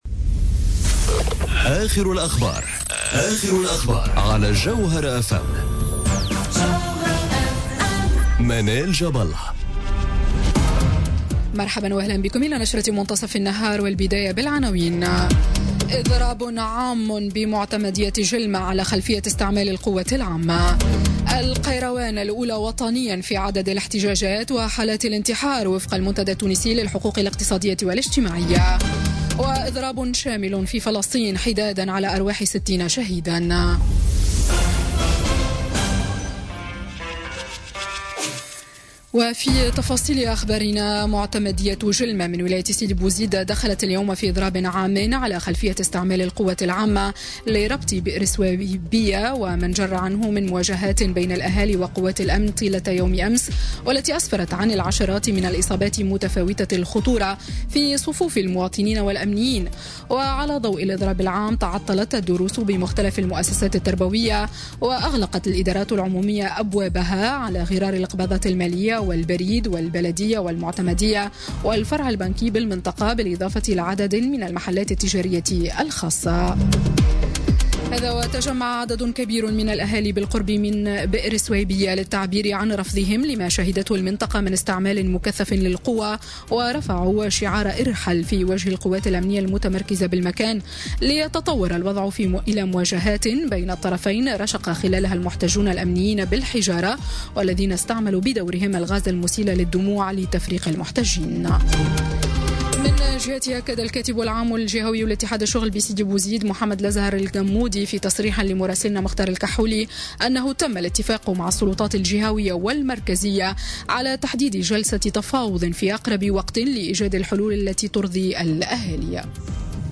journal info 12h00 du Mardi 15 Mai 2018